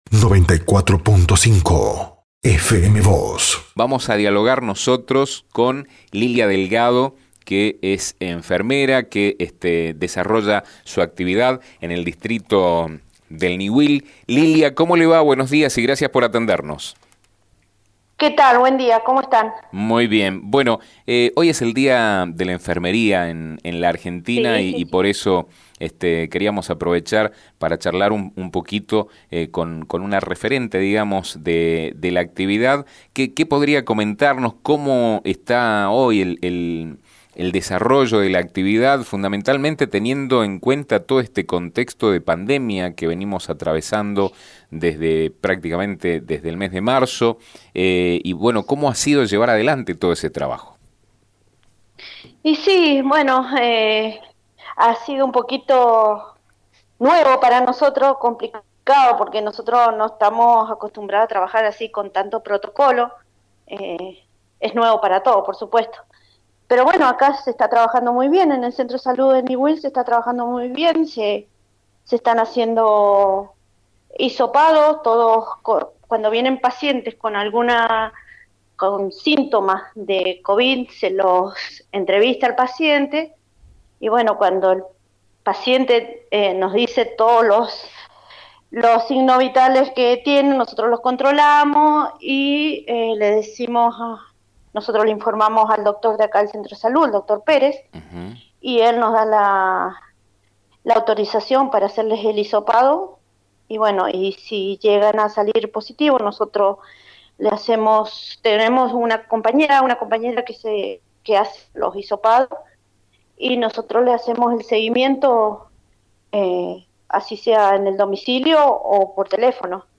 Ayer se celebró el Día Nacional de la Enfermería en el contexto de un año sumamente especial para el ámbito de la salud en nuestro país y el mundo. Sobre este tema habló con FM Vos (94.5) y con Diario San Rafael